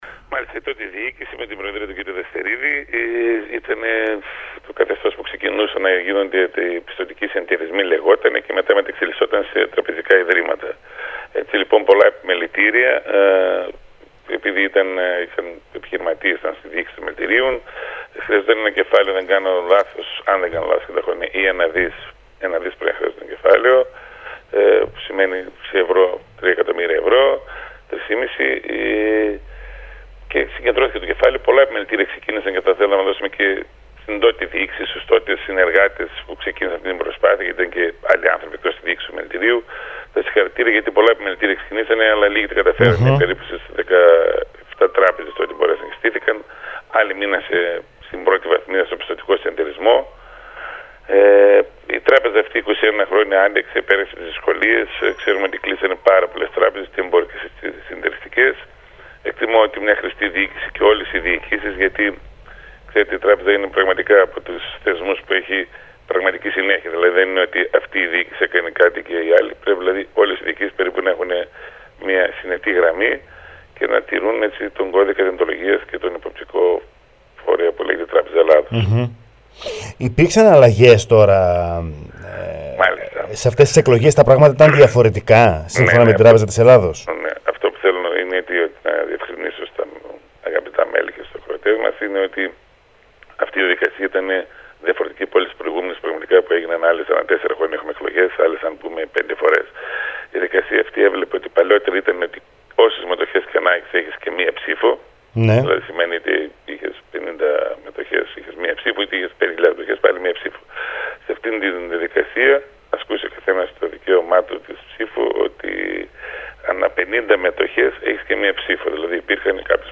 στο ραδιόφωνο Sferikos 99,3